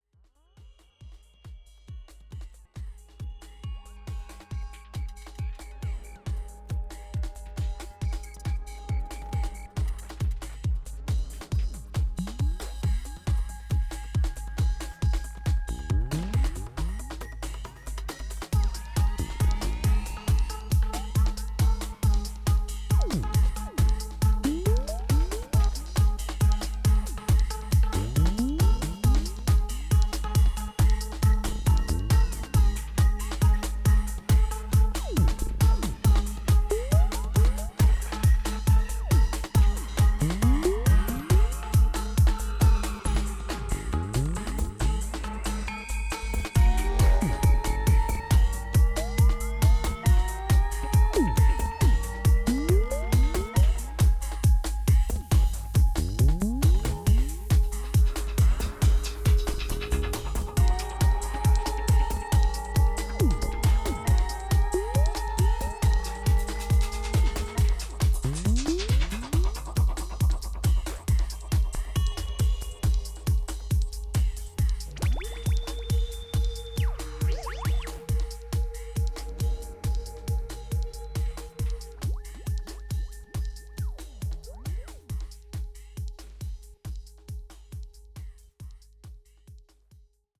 4/4キックとジャングルを分解したようなブレイクビーツのポリリズミカルな絡みが躍動感溢れる
オーガニックなテクスチャーを軸に卓越したグルーヴ感とサイケデリックな音響志向が見事に合致したテクノの新感覚を提示。